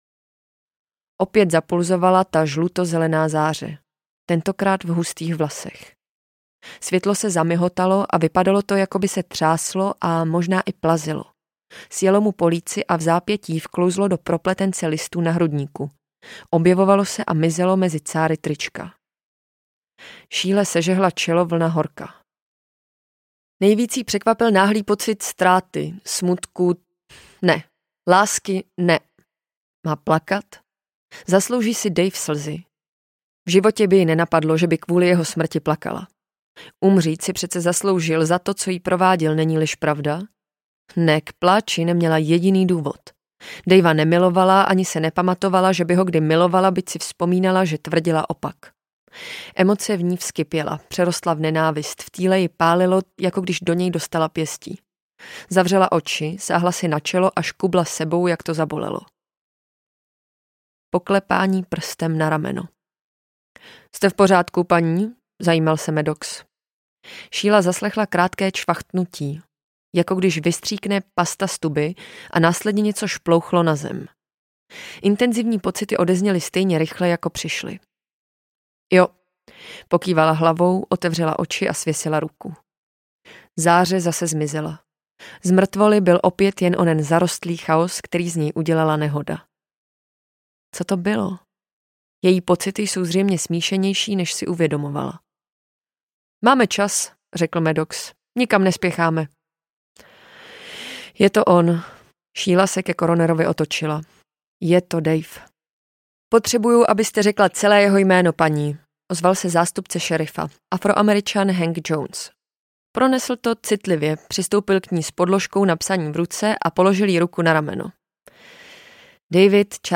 Tráva audiokniha
Ukázka z knihy